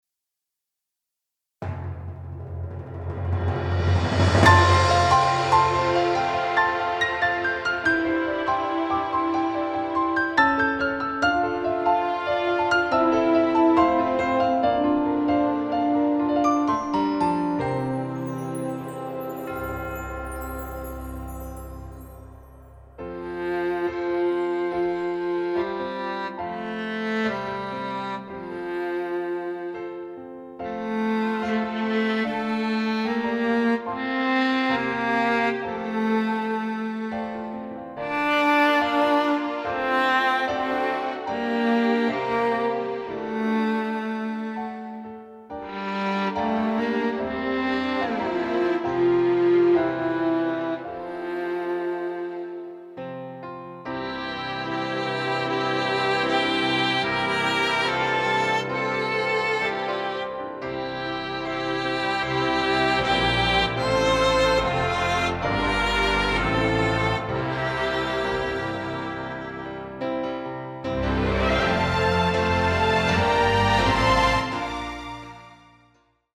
Listen to this short Pre-Mix as an example (The Appassionata Strings, some Brass instruments, Piano, solo Viola, solo Violin, Percussion...)
Either way it sounds different to MIR, but it also comes with a nice depth and transparency, warm strings...and this without all the gadgets of MIR.
And I also offered a mp3-menu, done with several ingredients but also with a little Ketchup as well (percussion).